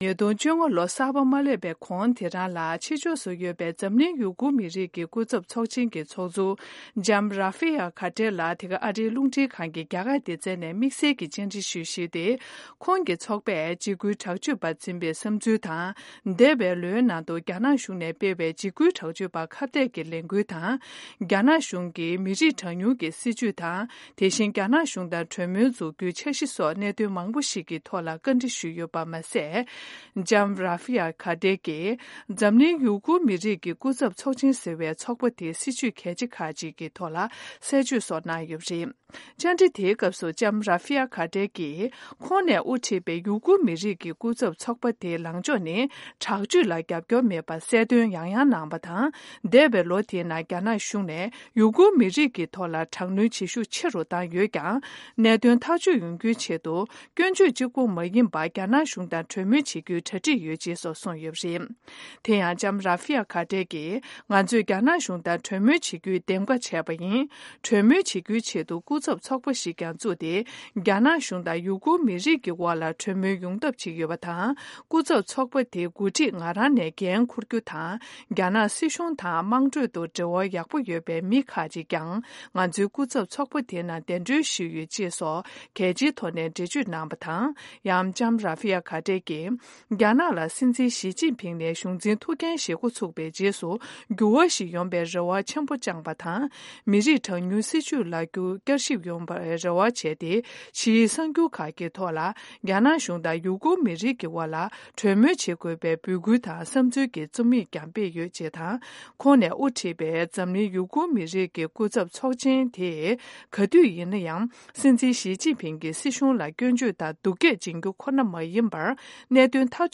ཡུ་གུར་པའི་དབུ་ཁྲིད་རེ་བི་ཡ་ཁ་དིར་ལ་བཅར་འདྲི།